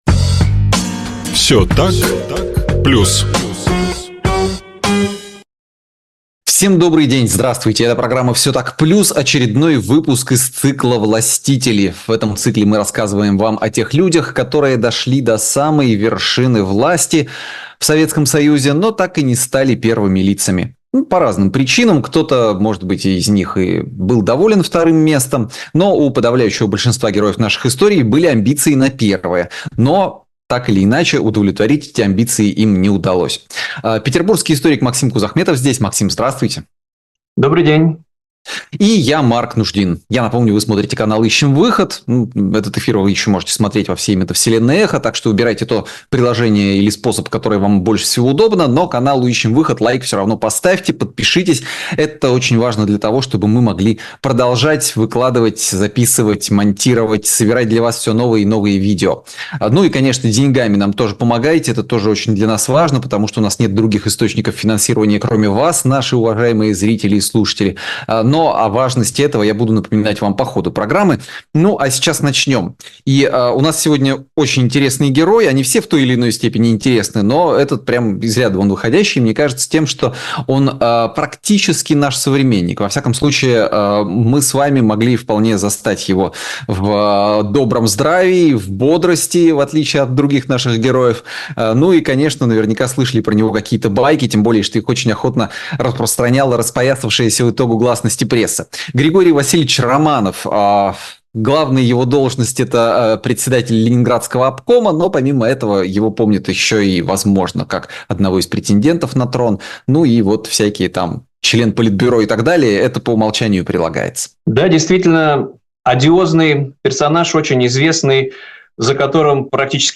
Эфир